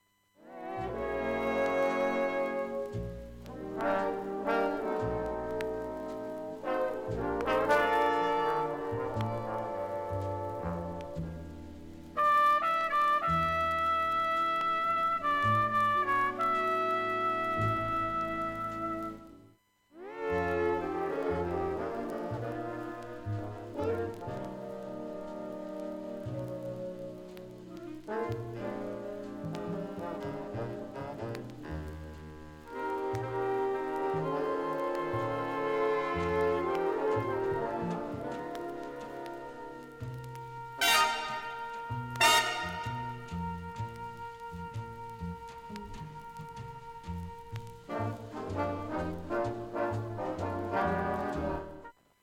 ほかかすかなサッという音が3回 かすかな単発のプツが６箇所